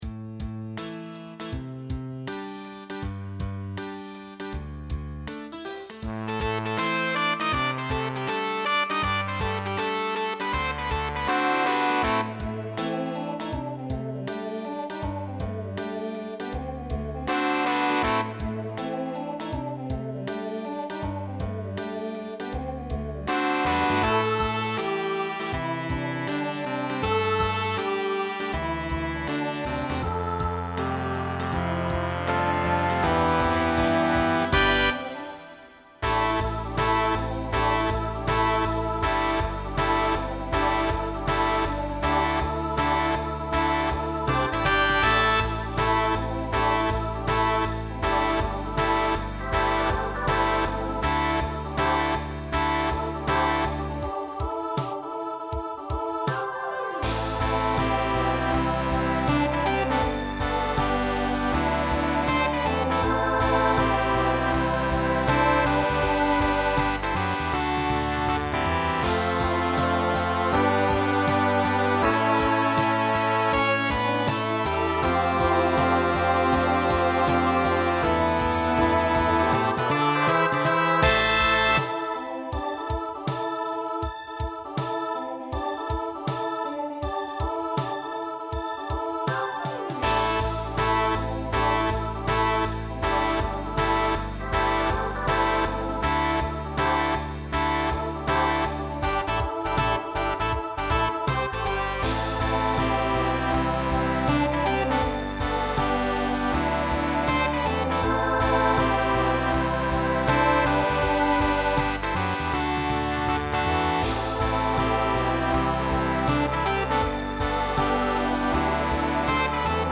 Voicing SSA Instrumental combo Genre Country
Mid-tempo